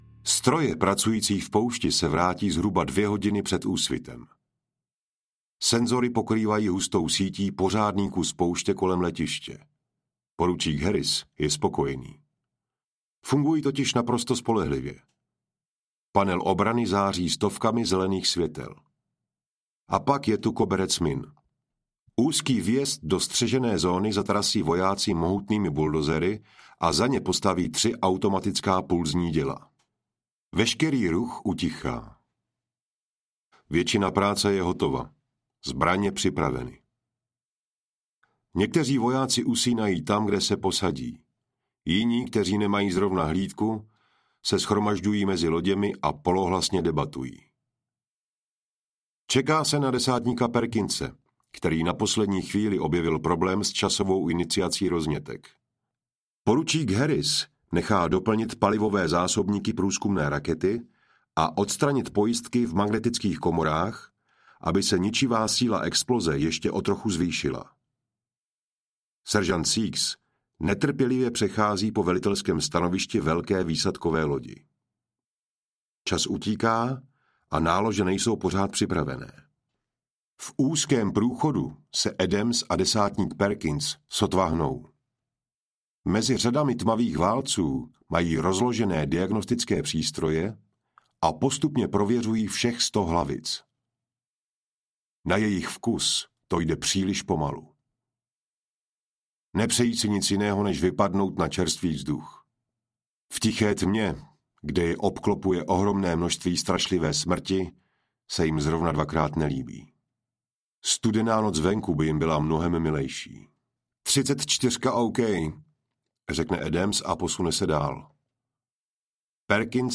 Mariňáci 2 audiokniha
Ukázka z knihy